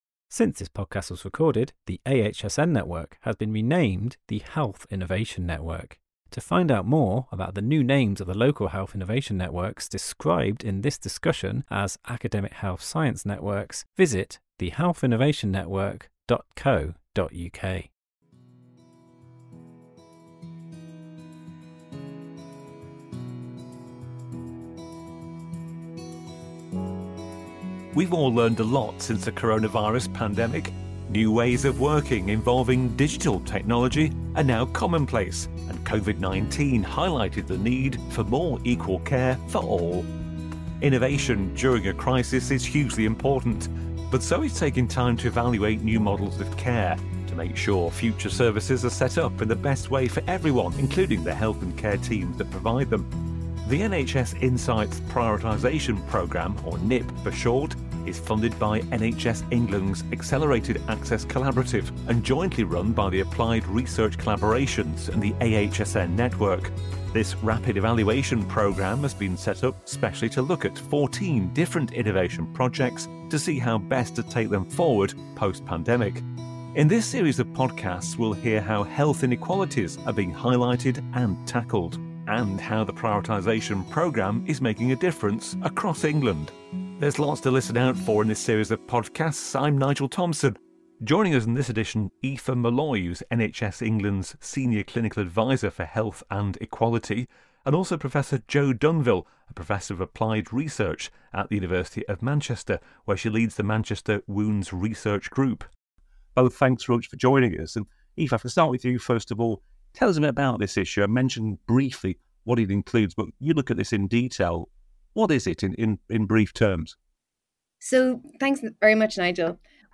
In this episode, four guest discuss health inequalities in England.